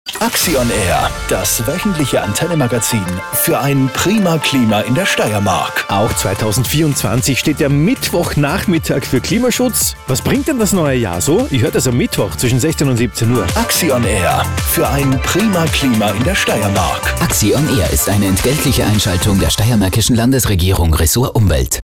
Muster Hörfunkspot "Axi on Air"
Muster-Hörfunk-Spot Antenne-Axi on Air.MP3